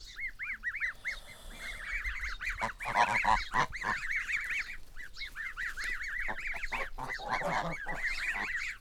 На этой странице собраны звуки гусей – от привычного бормотания до громких криков.
Подборка включает голоса как домашних, так и диких гусей, обитающих у водоемов.
Звуки гусят